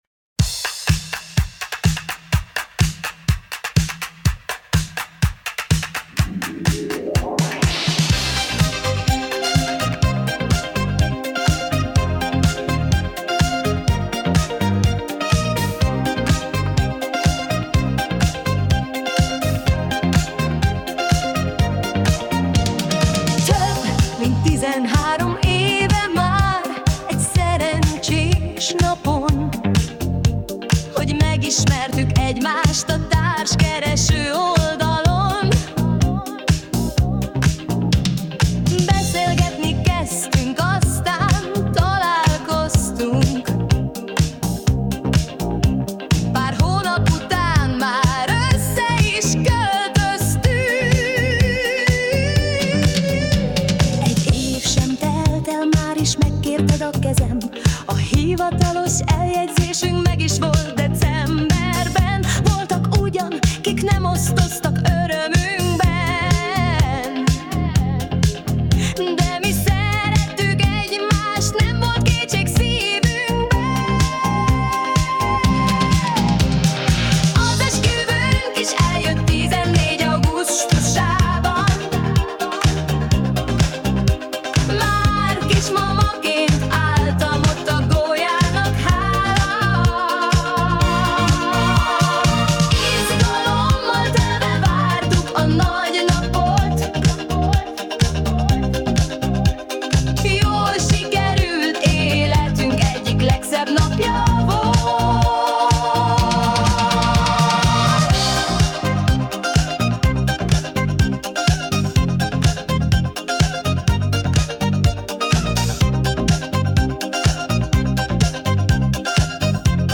Synth Pop - Évfordulóra
Személyre szabott ajándék dal - Alkalom: évforduló